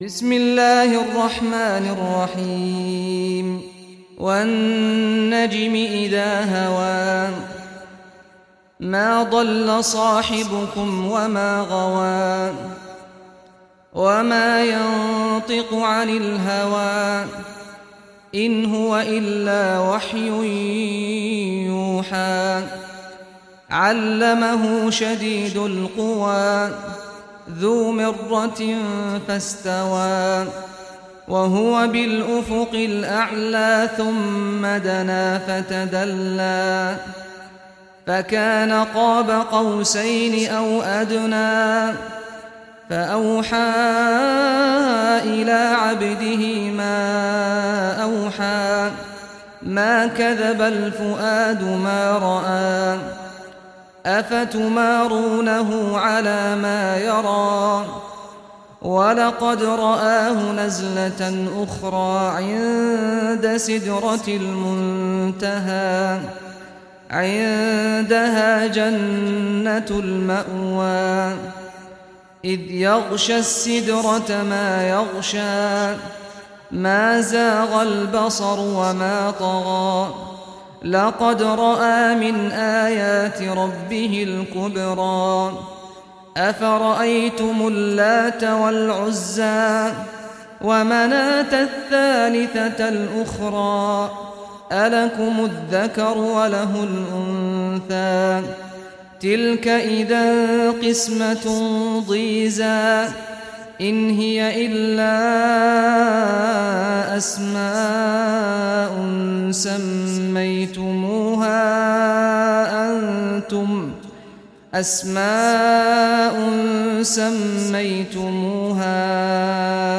Surah An-Najm Recitation by Sheikh Saad Ghamdi
Surah An-Najm, listen or play online mp3 tilawat / recitation in Arabic in the beautiful voice of Sheikh Saad al Ghamdi.